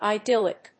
音節i・dyl・lic 発音記号・読み方
/ɑɪdílɪk(米国英語)/